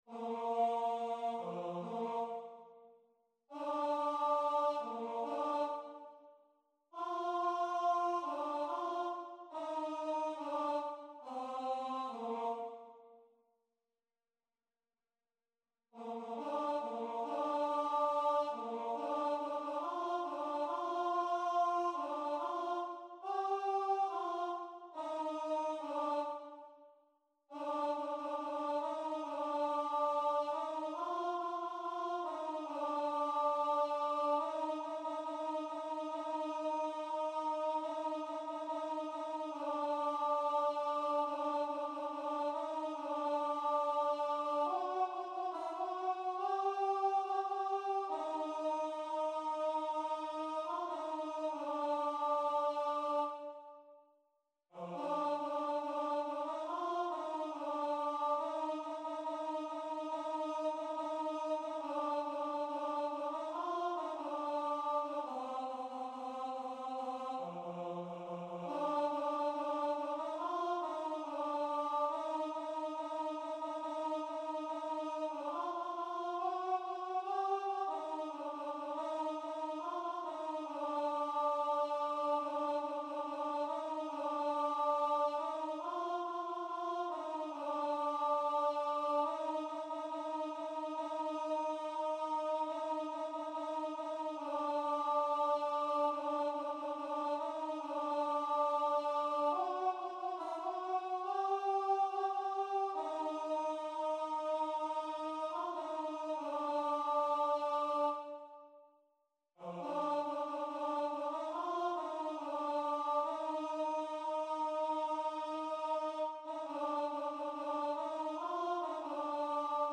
This Little Light (Tenor 1)